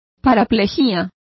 Complete with pronunciation of the translation of paraplegias.